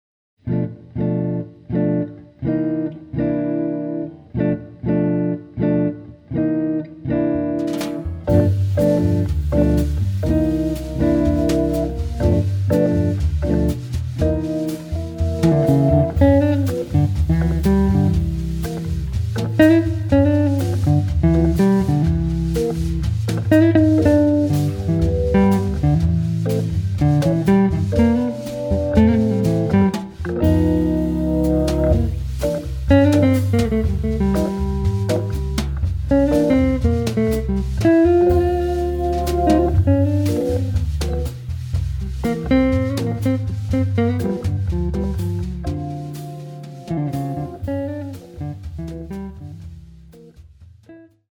guitar
Hammond organ
drums